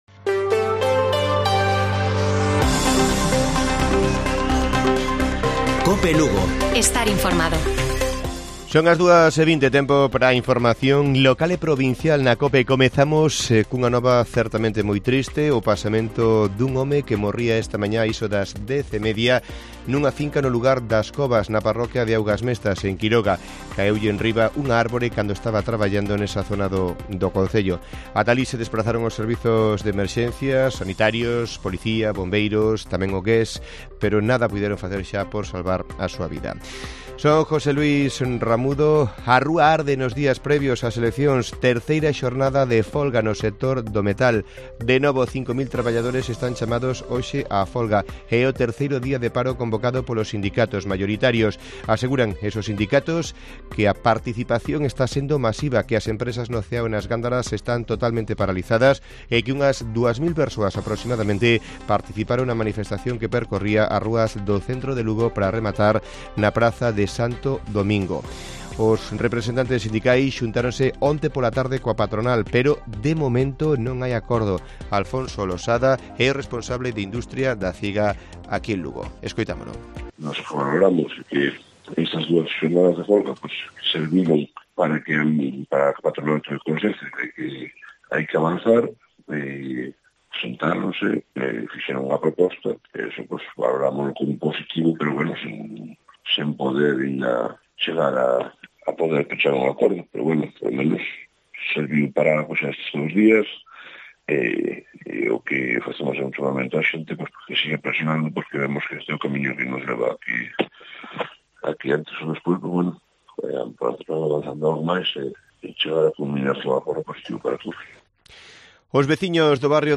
Informativo Mediodía de Cope Lugo. 25 DE MAYO. 14:20 horas